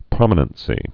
(prŏmə-nən-sē)